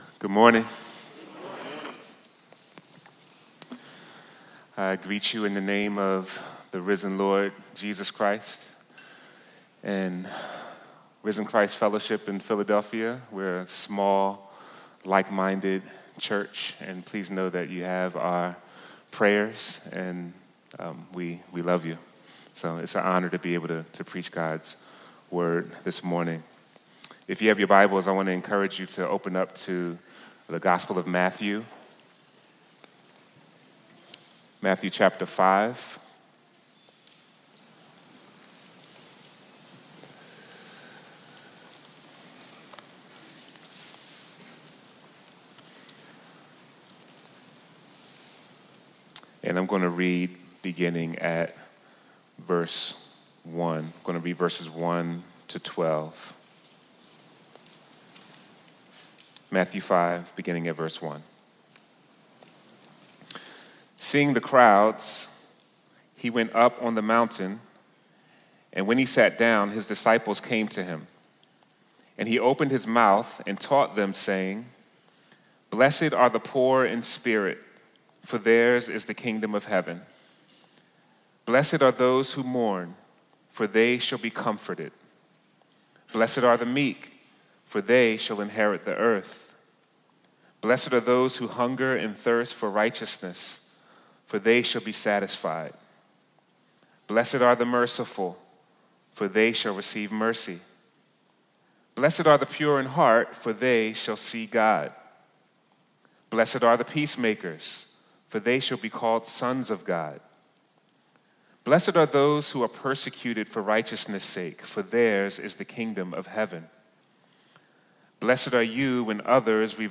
This sermon will ask and answer three questions related to mourning from the Beatitudes. What does the character of the of the citizens of God's kingdom look like?